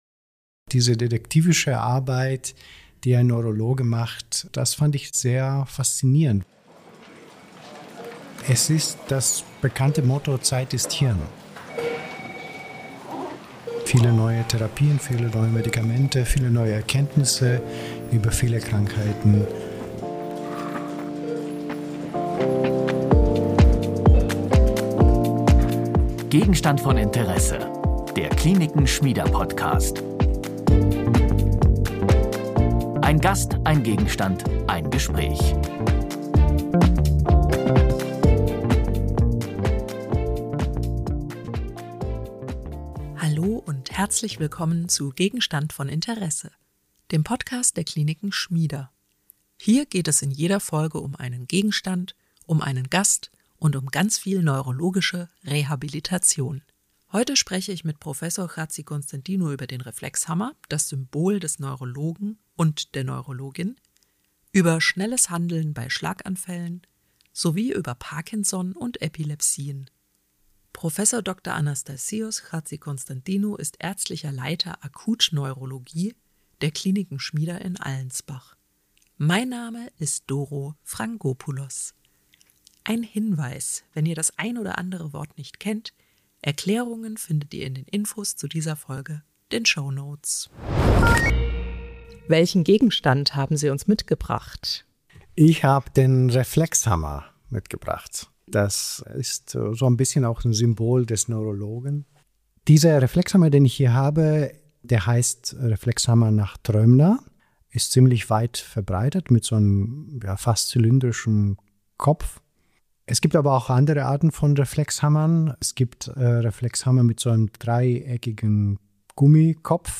Im Gespräch räumt er zudem mit Vorurteilen über Epilepsie auf, spricht über die Behandlung von Parkinson und erklärt, warum bei Schlaganfällen jede Sekunde zählt.